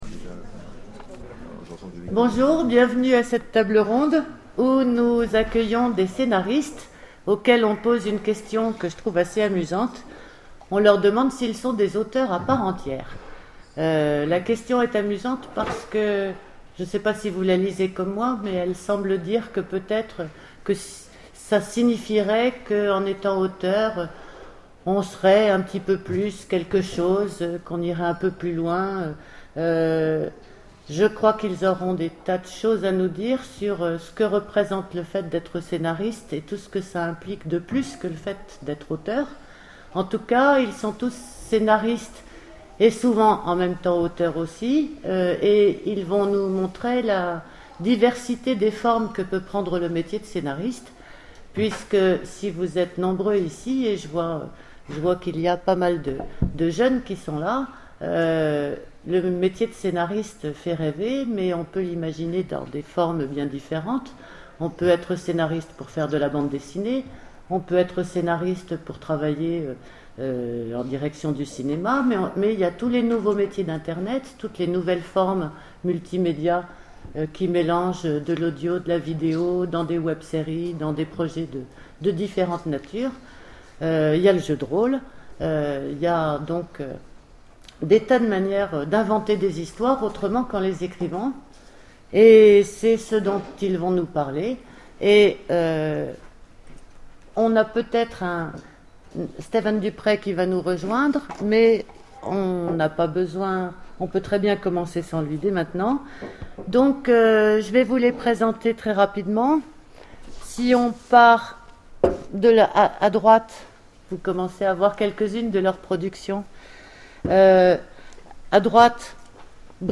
Imaginales 2012 : Conférence Scénaristes